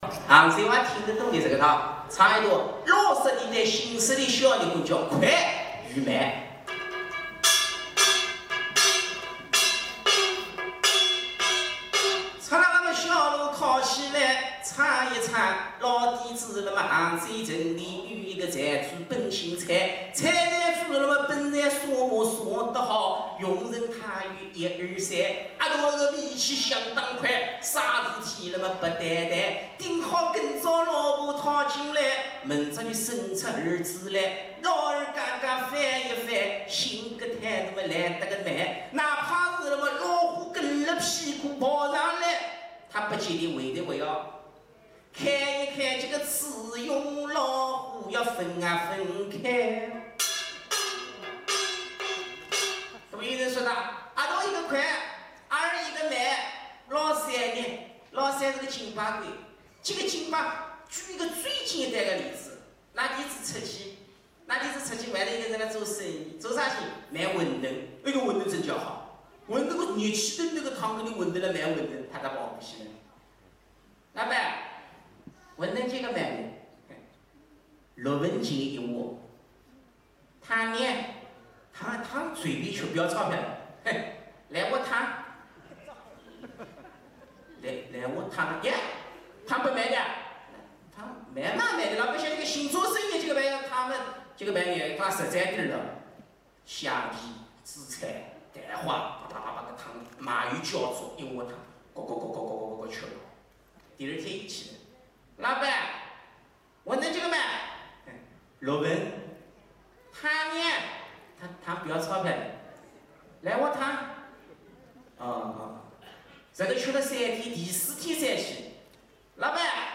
01小热昏mp3